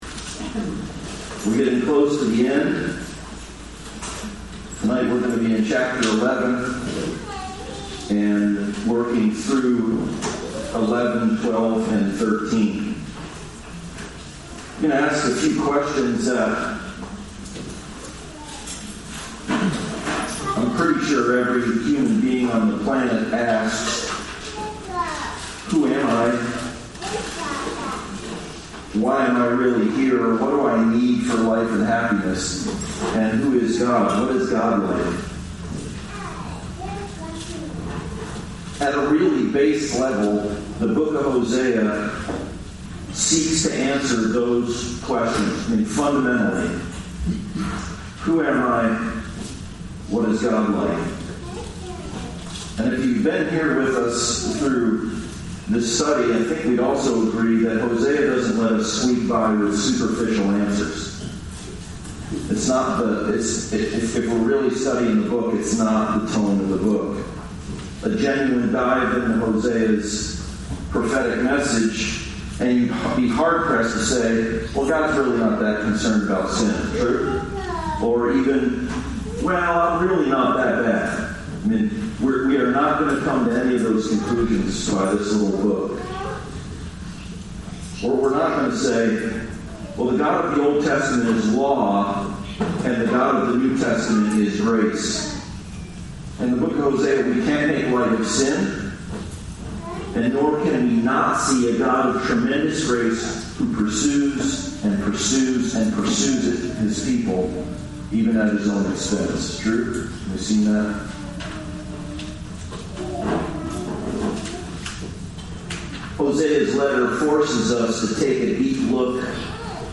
Passage: Hosea 11:1-13:16 Service Type: Sunday Service « Generous Grace